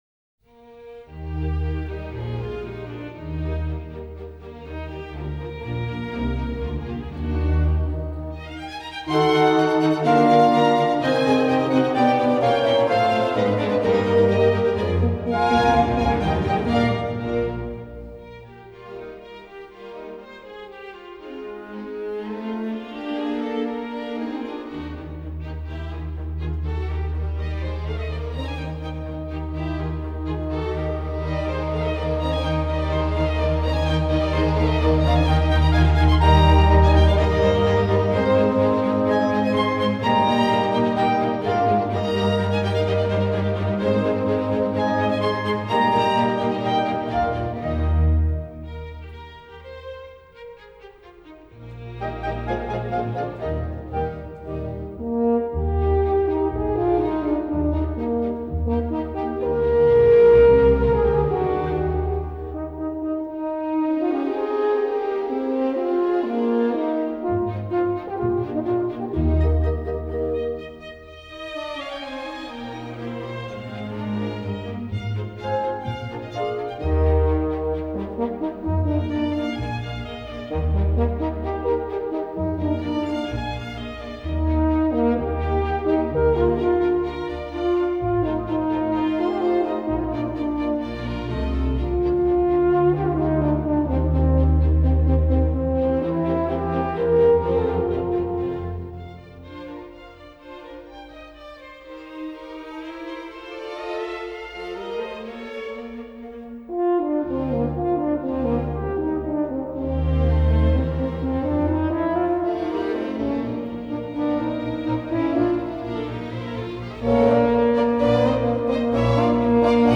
Classical
Orchestra
horn) Orquestra Metropolitana de Lisboa